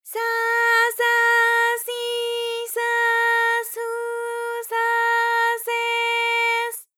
ALYS-DB-001-JPN - First Japanese UTAU vocal library of ALYS.
sa_sa_si_sa_su_sa_se_s.wav